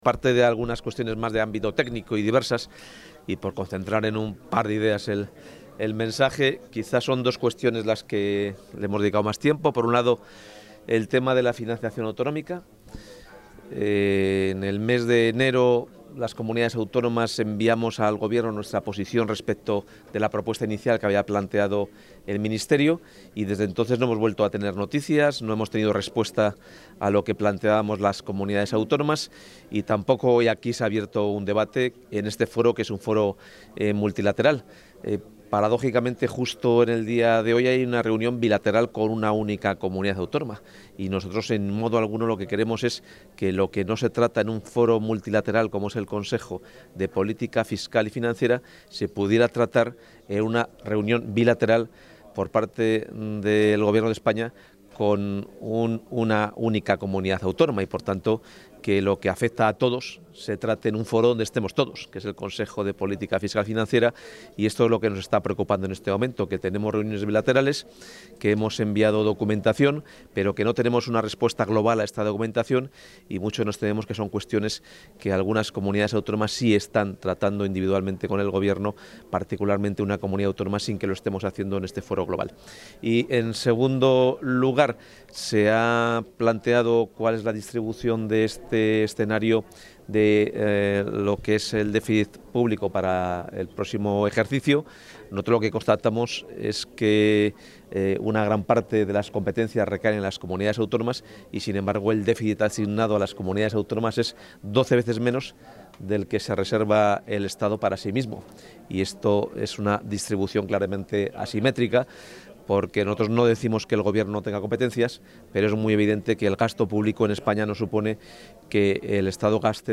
Audio consejero de Economía y Hacienda.